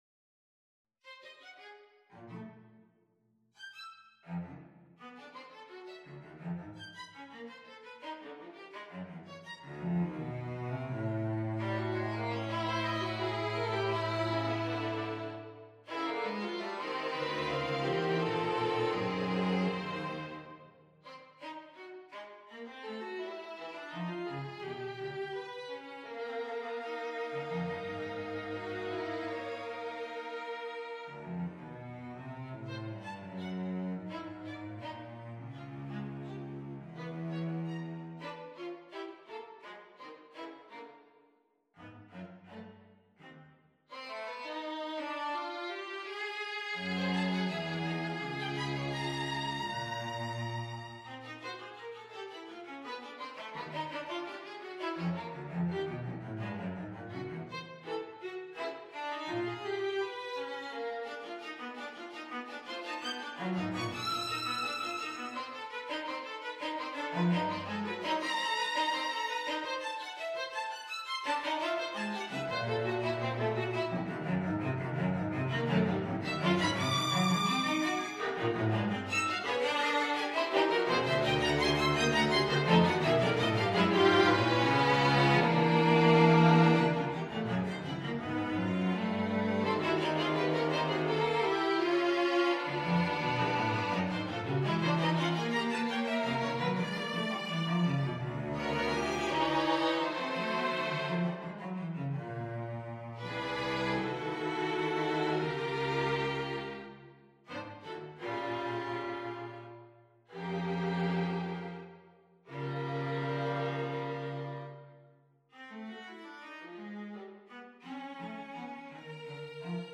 Quartet for Strings No.13
Quartet for Strings No.13 on a purpose-selected tone row Op.104 1. Allegro molto - Meno mosso - Tempo Primo - Maestoso 2. Scherzo. Presto assai - Trio. Prestissimo - Tempo Primo 3. Lento molto - Largo con moto - Tempo Primo 4. Presto ma non troppo - Allegro ma non troppo - Tempo Primo - Tempo Secondo Date Duration Download 8 May 2025 27'36" Realization (.MP3) Score (.PDF) 25.2 MB 1.2 MB